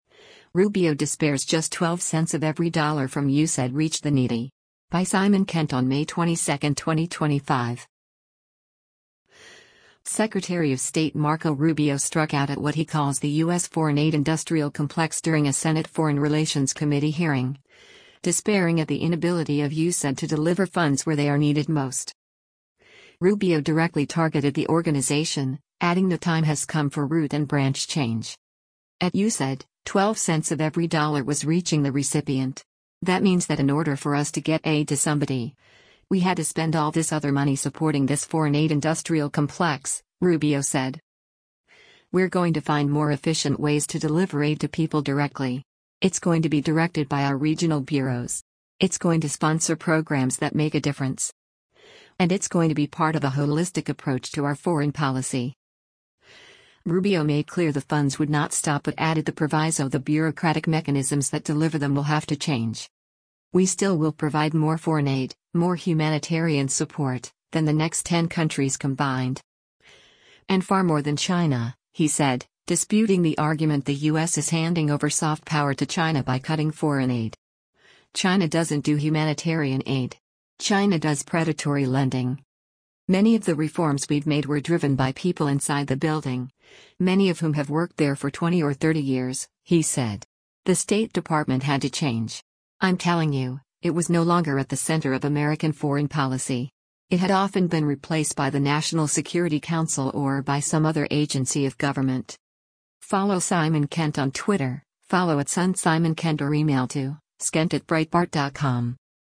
Secretary of State Marco Rubio struck out at what he calls the U.S. “foreign aid industrial complex” during a Senate Foreign Relations Committee hearing, despairing at the inability of USAID to deliver funds where they are needed most.